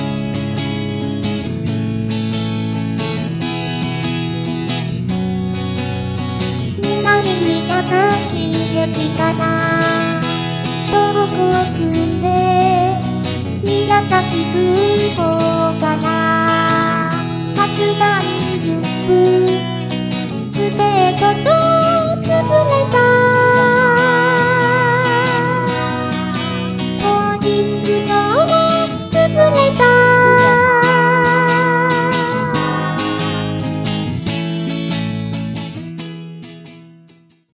せっかく体験版を入手しましたなので調教してみたってことでした。曲は宮交シティのうた。
初音さんがぷち間違いをしてくれたのでこれも。